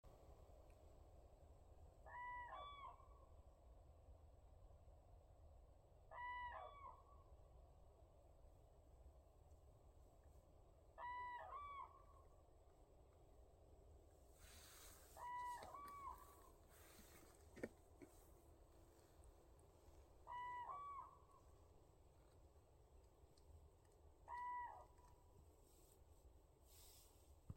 Common Crane, Grus grus
Ziņotāja saglabāts vietas nosaukumsLielais Ķemeru tīrelis
StatusVoice, calls heard